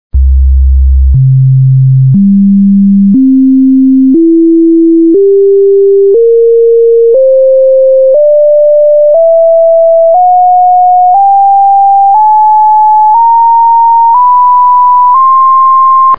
(1. bis 16. Partialton) und
Partialtonreihe: 1. bis 16. Teilton